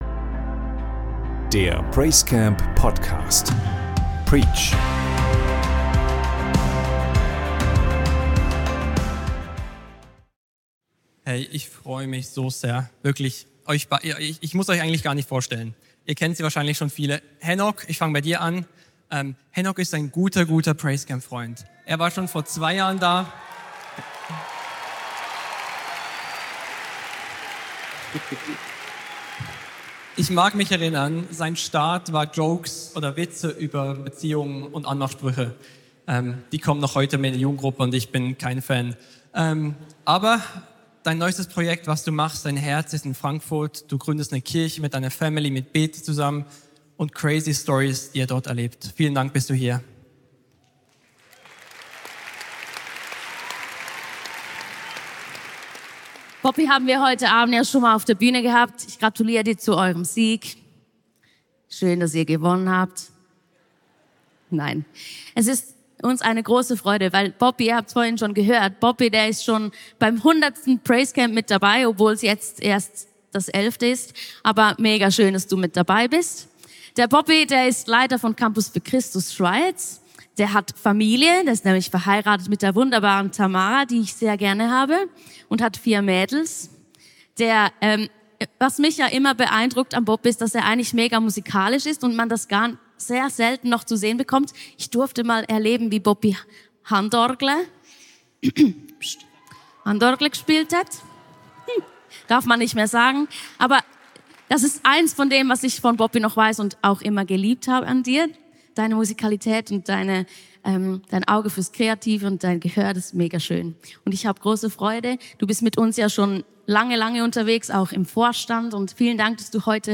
Tauche tief ins PraiseCamp 2024 ein - Preaches, Talks, Q&As und Insights warten auf dich!